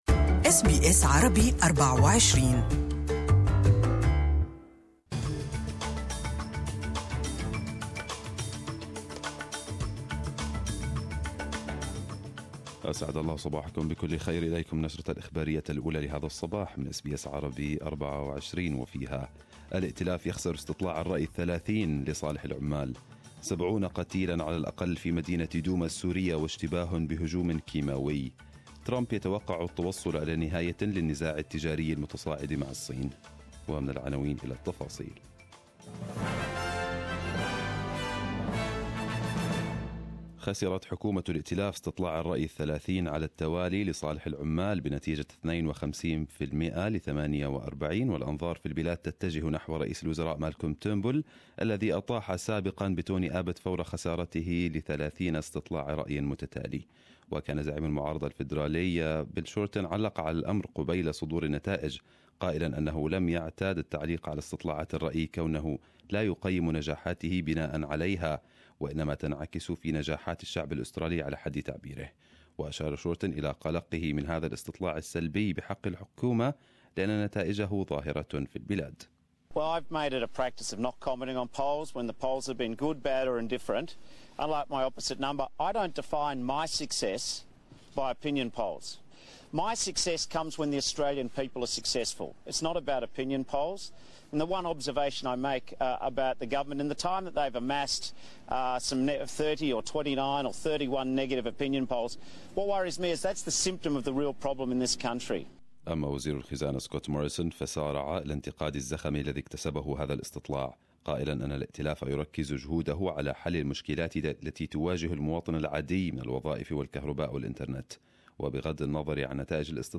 Arabic News Bulletin 09/04/2018